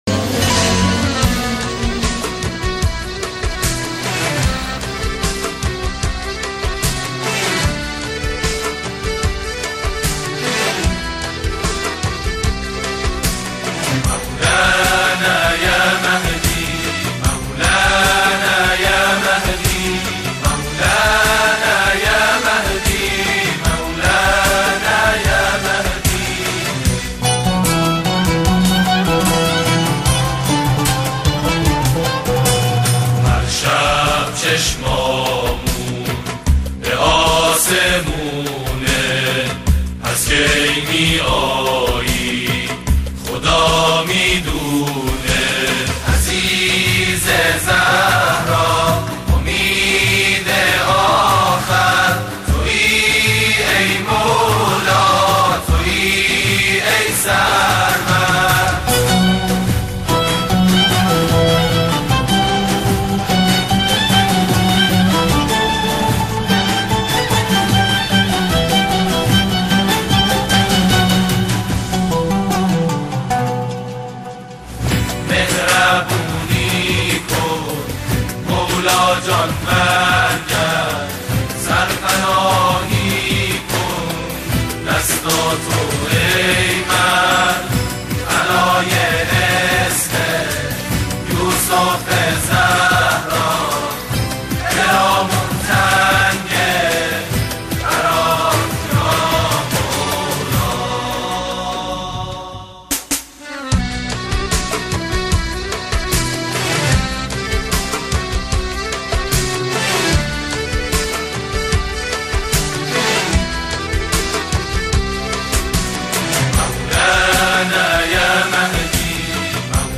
نوای تواشیح"مولانا یا مهدی"